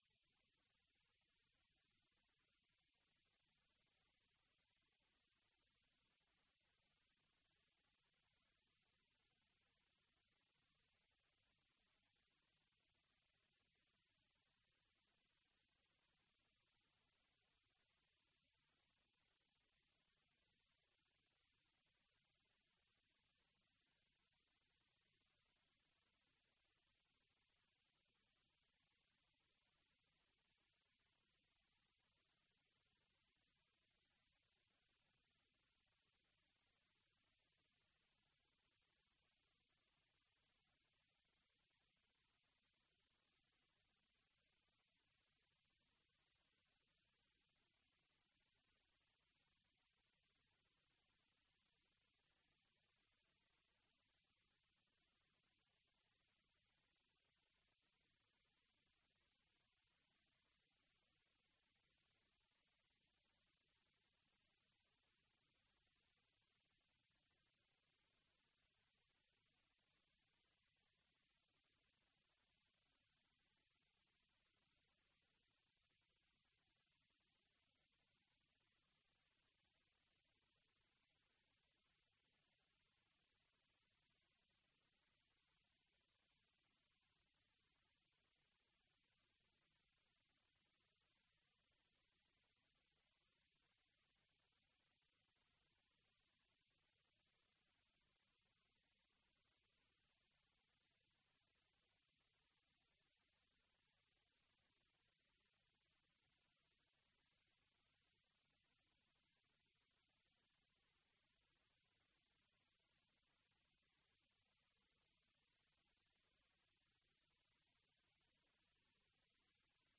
Committee Planning Committee Meeting Date 29-10-24 Start Time 2.00pm End Time 3.56pm Meeting Venue Coltman VC Room, Town Hall, Burton upon Trent Please be aware that not all Council meetings are live streamed.
Meeting Recording 241029.mp3 ( MP3 , 26.37MB )